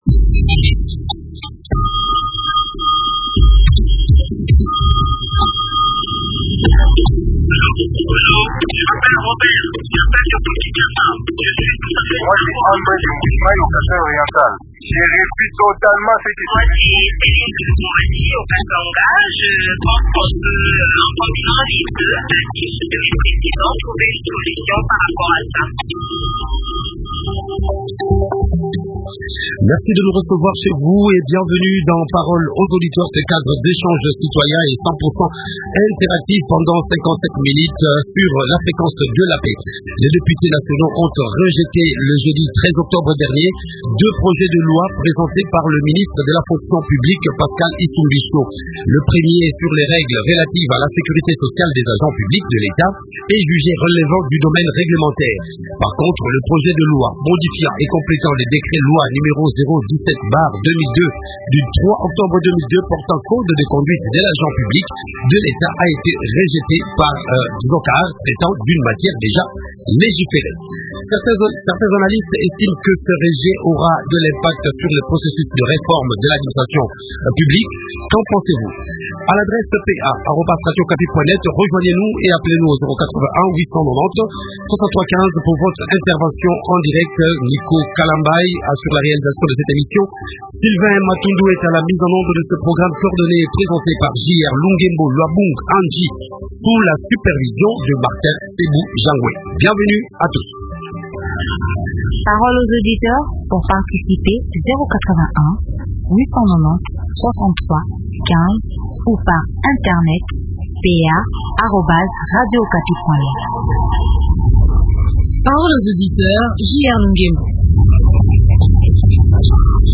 Invité : Pascal Isumbisho, Ministre de la Fonction Publique